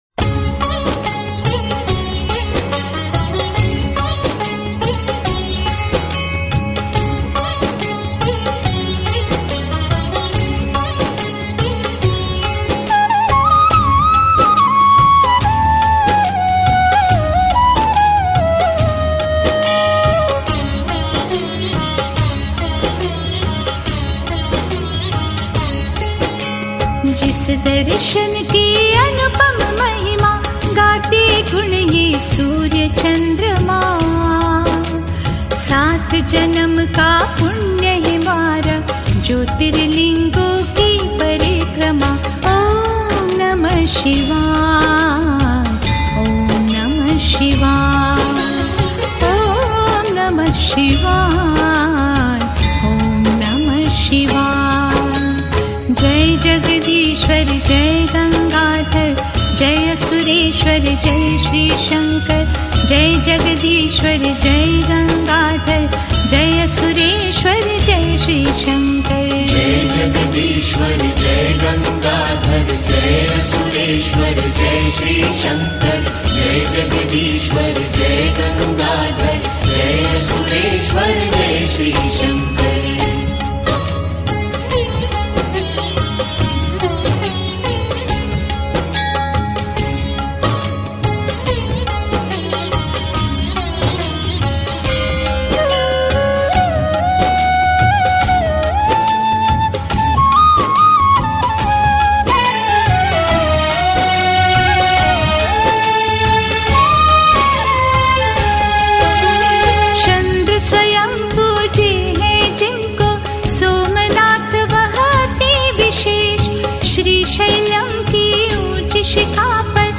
Devotional Songs > Shiv (Bholenath) Bhajans